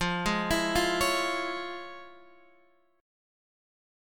FmM7#5 Chord